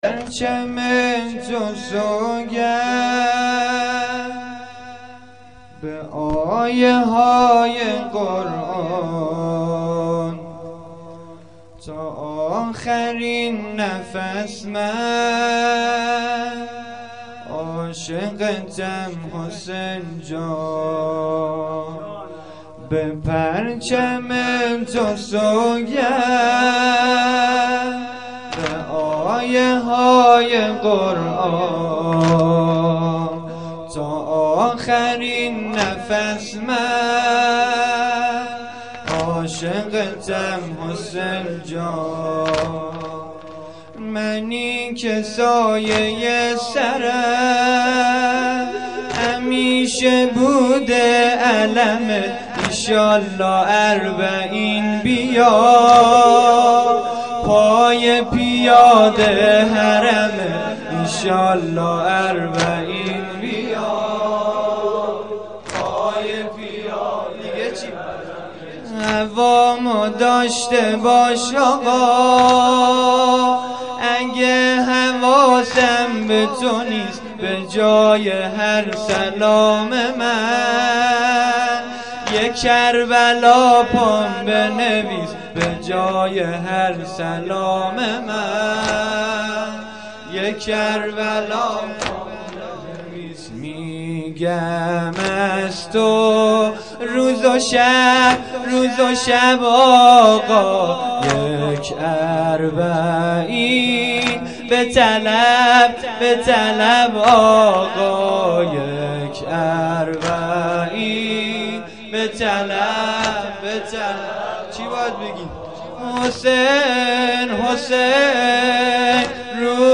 جلسه مذهبی زیارت آل یاسین باغشهر اسلامیه